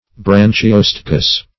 Branchiostegous \Bran`chi*os"te*gous\